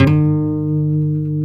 E 3 HAMRNYL.wav